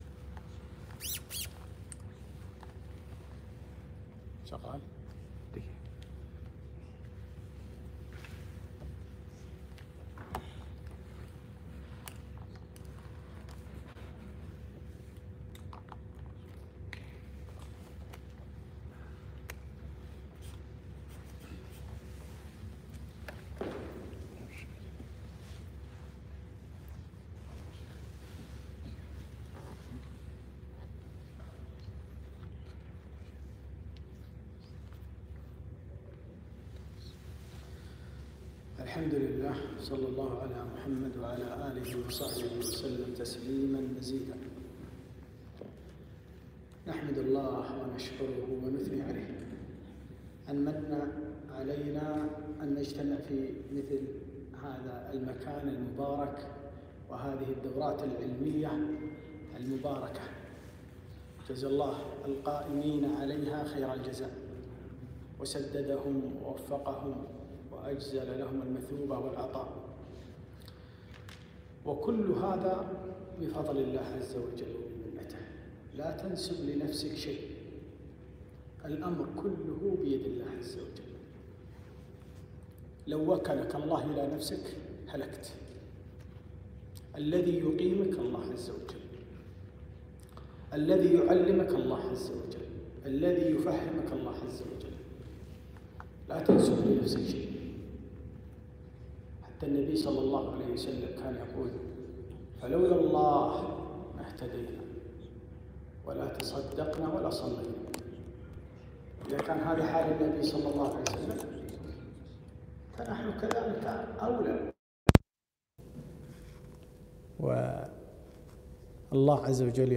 محاضرة - آداب طلب العلم